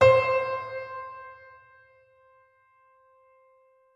piano-sounds-dev
c4.mp3